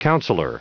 Prononciation du mot counsellor en anglais (fichier audio)
Prononciation du mot : counsellor